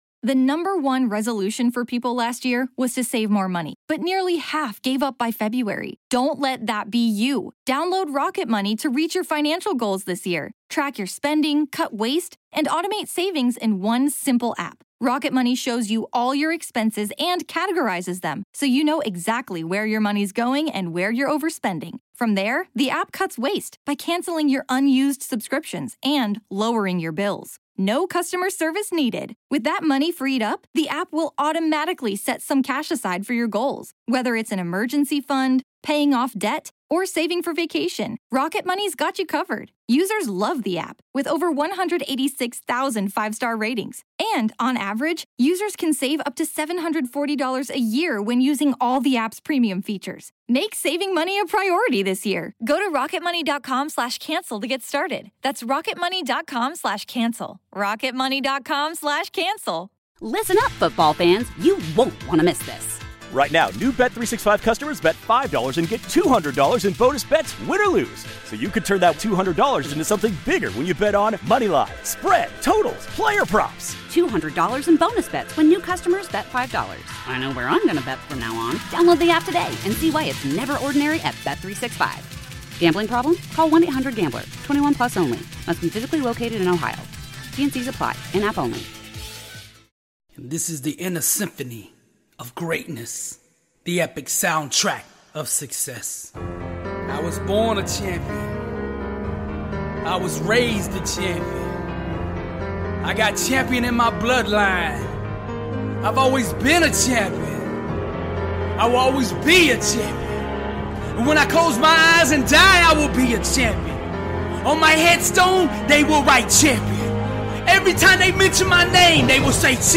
This is a powerful compilation of the best motivational speeches